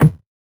CS_VocoBitA_Hit-14.wav